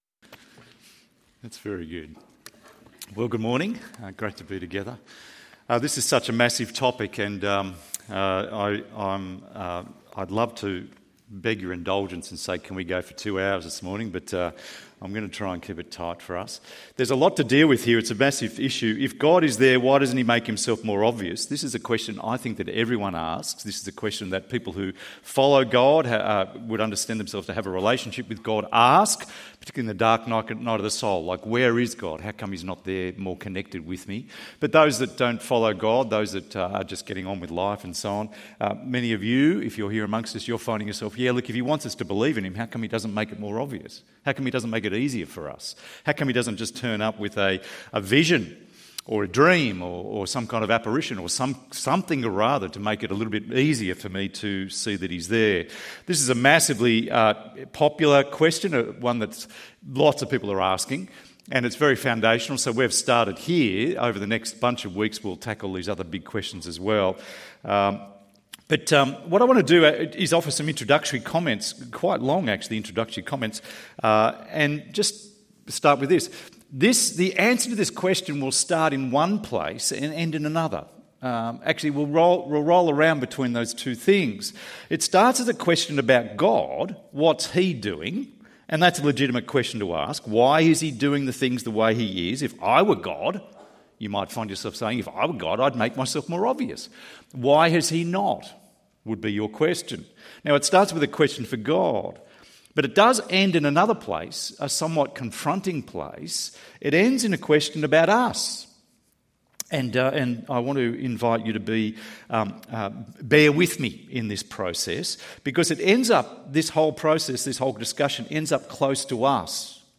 Why isn't it more obvious that God is there? ~ EV Church Sermons Podcast